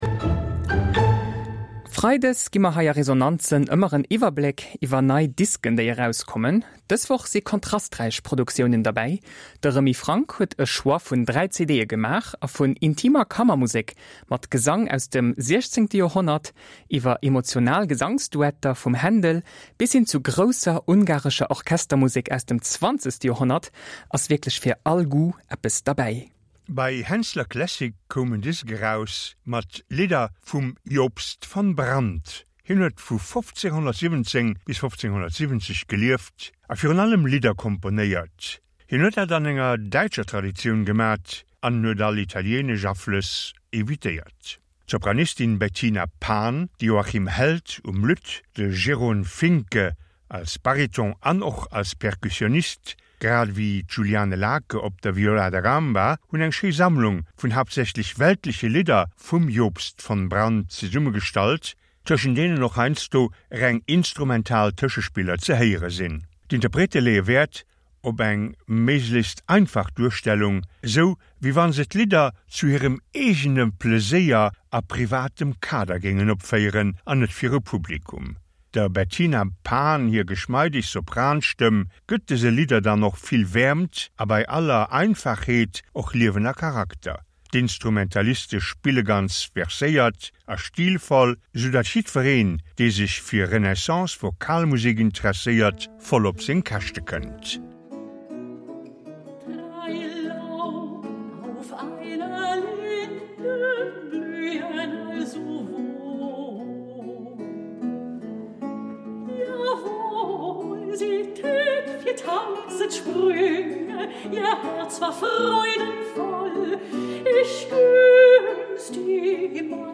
fir Sopran a Countertenor